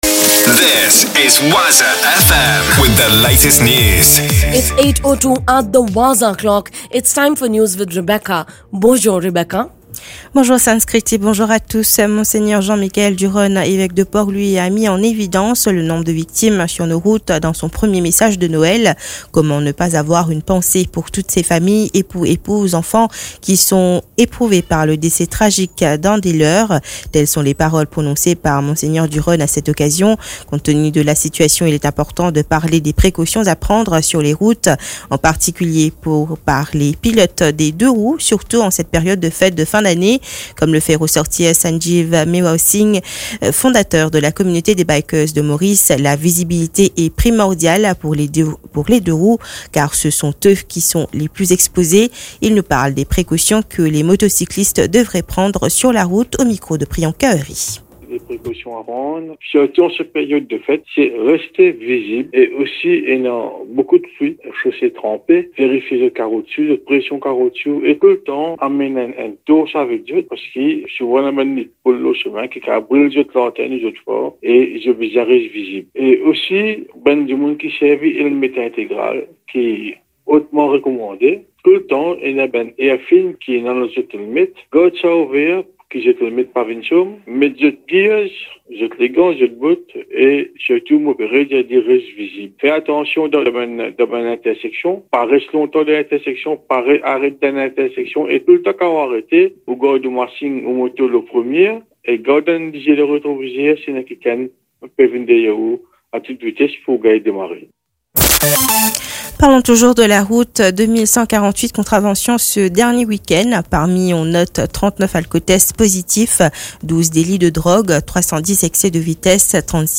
NEWS 8h - 26.12.23